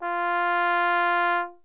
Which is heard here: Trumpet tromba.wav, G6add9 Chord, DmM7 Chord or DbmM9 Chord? Trumpet tromba.wav